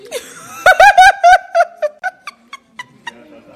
risada_vkicQww.mp3